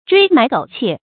椎埋狗竊 注音： ㄔㄨㄟˊ ㄇㄞˊ ㄍㄡˇ ㄑㄧㄝ ˋ 讀音讀法： 意思解釋： 謂搶殺偷盜，不務正業。